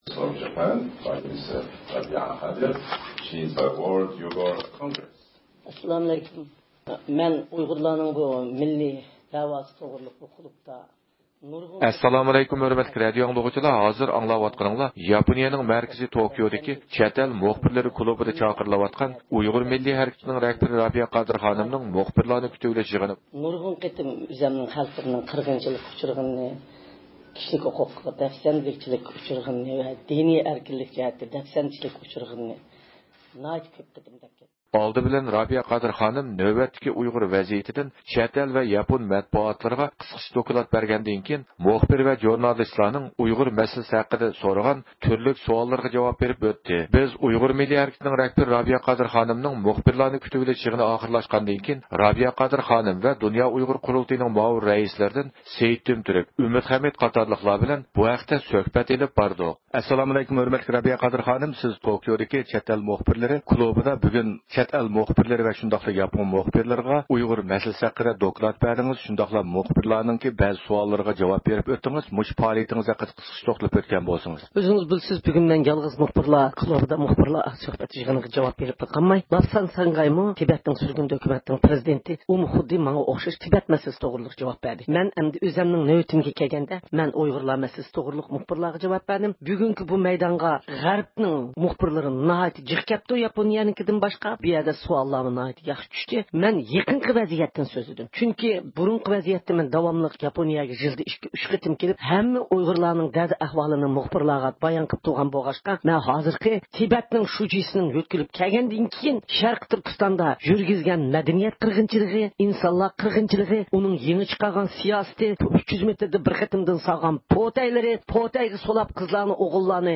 مەنبە: ئەركىن ئاسىيا ردىئوسى